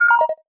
▶ Fixed - Added in a check for every time there is a collision between the parent-ator and another entity 2) Sound almost unhearable due to distortion ▶ Fixed - Changed audio to 16 bit mono 3) The Parent-ator model rezzes pointing at the user's face.
parent-tool-sound2.wav